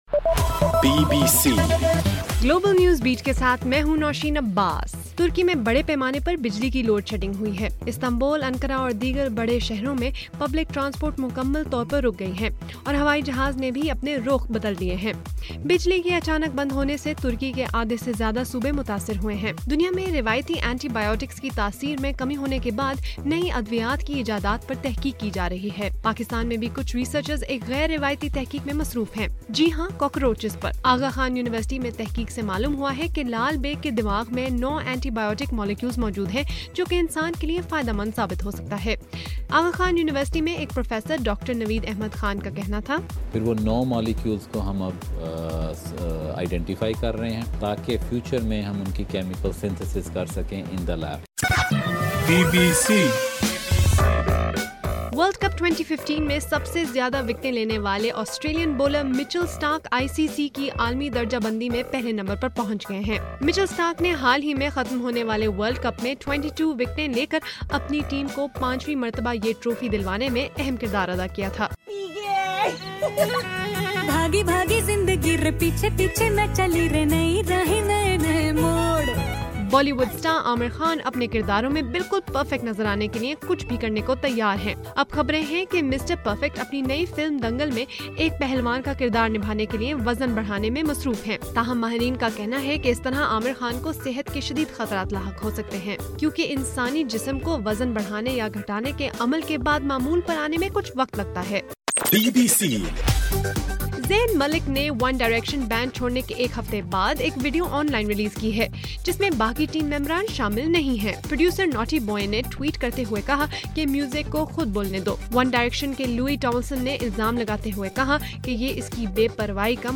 مارچ 31: رات 9 بجے کا گلوبل نیوز بیٹ بُلیٹن